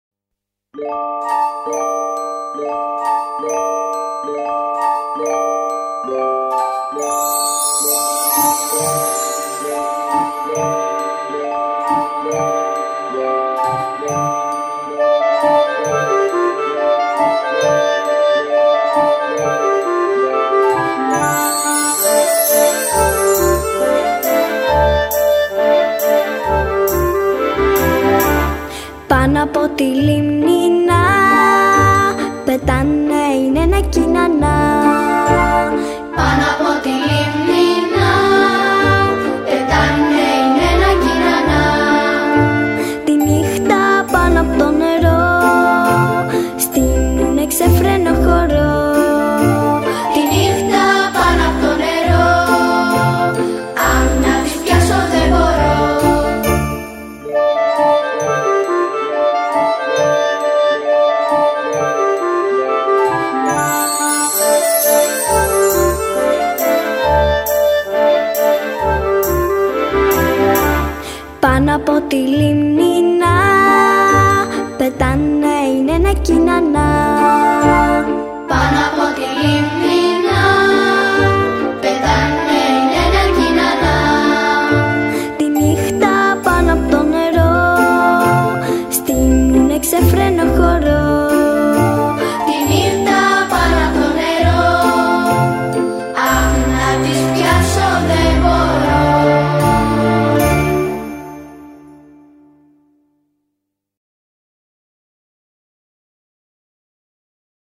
μελοποιημένα αποσπάσματα
αλλά και παιδιά δημοτικών σχολείων.